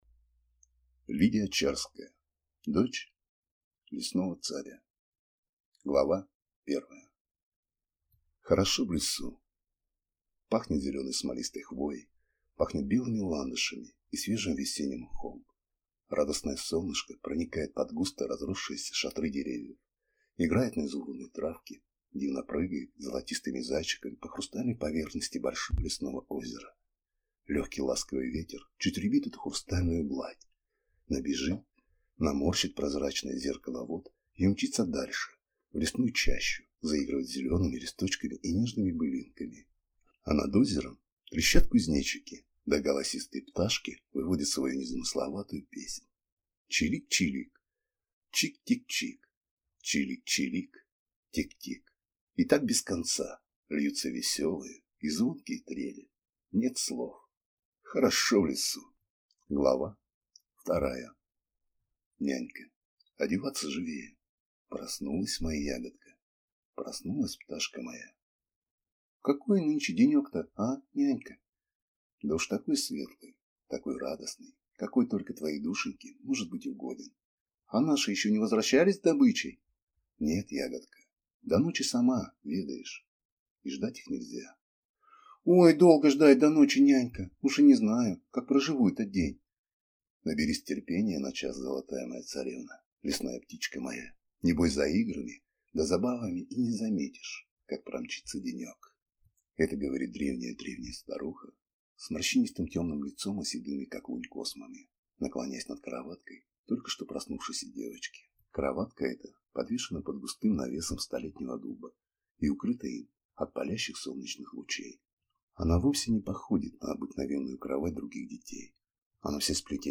Аудиокнига Дочь лесного царя | Библиотека аудиокниг